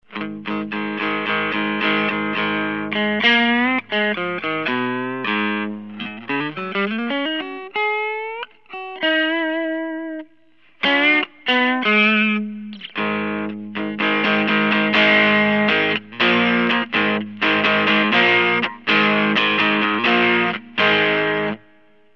Clean Sounds
Gain is set at minimum so you can hear the pedal acting as a boost.
All clips recorded through my AX84 tube amp set for a clean neutral tone using a stock 1970 Fender Stratocaster - miked with an SM-57. All distortion is being produced by the pedal.